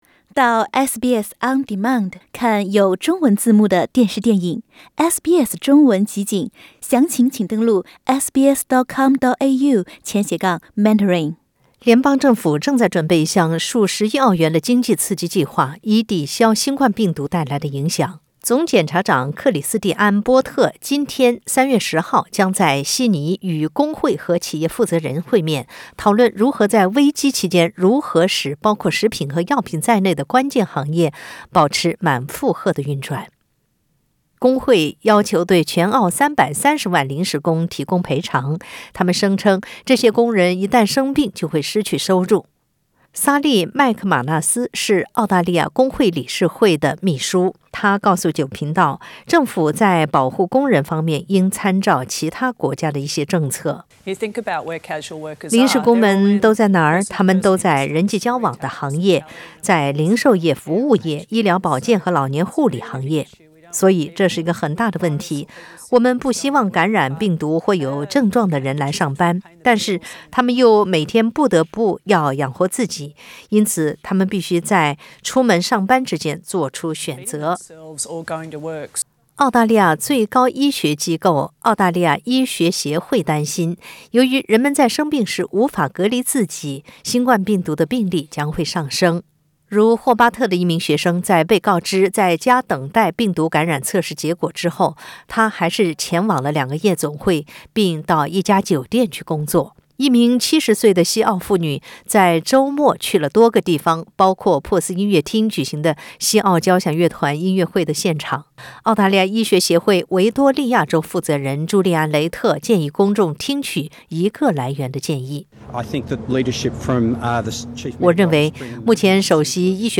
总检察长Christian Porter 今天（3月10日）在悉尼与工会和企业负责人会面，讨论危机期间如何确保食品和药品等关键行业保持满负荷运转。点击上方图片收听音频报道。